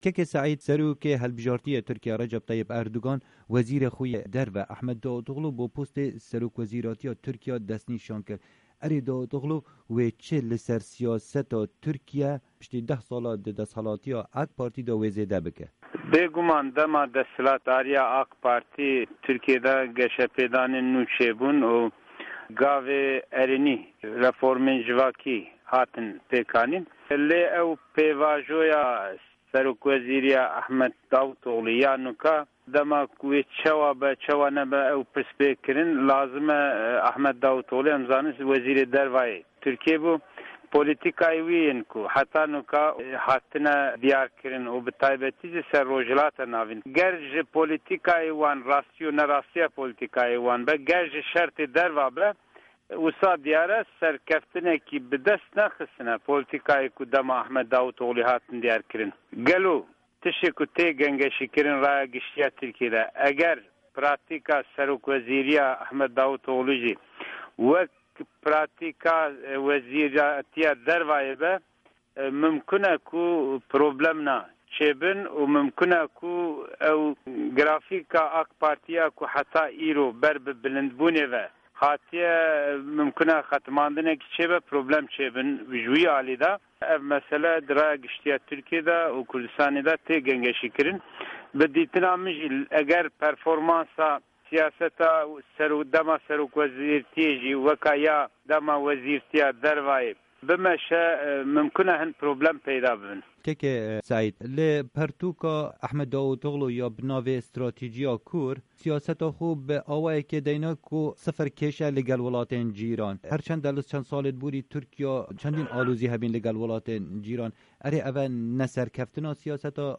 hevpeyv'n